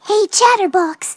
synthetic-wakewords
ovos-tts-plugin-deepponies_Fluttershy_en.wav